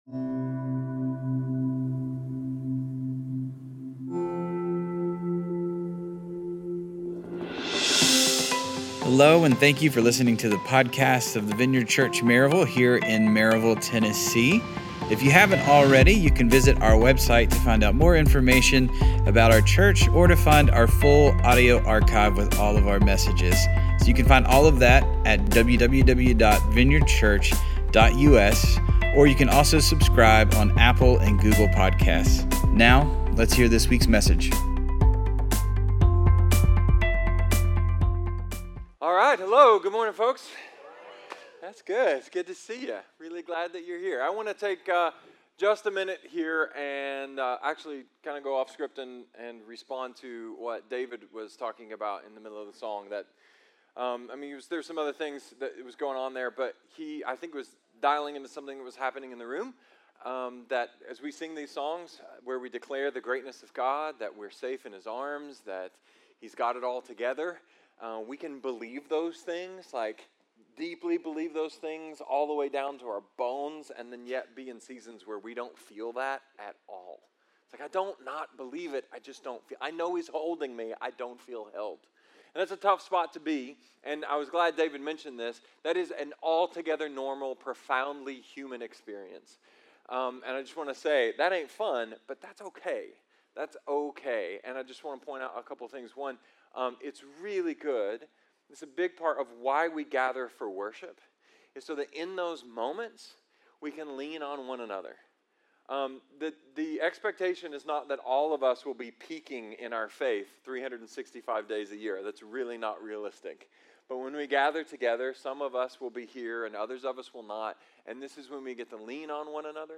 A sermon about the things we may not notice but ultimately transform everything, the dangers of cruise control, and the ‘why’ behind spiritual practices